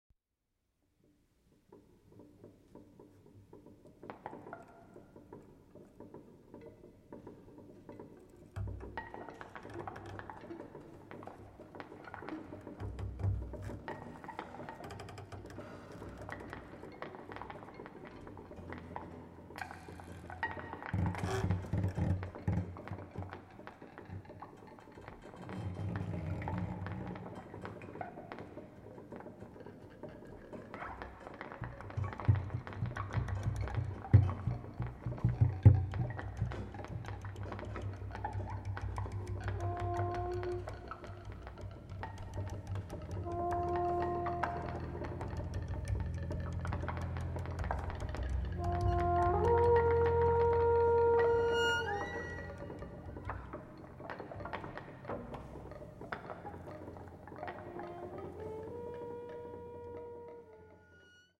recorded October 1, 2024 in Jordan Hall, Boston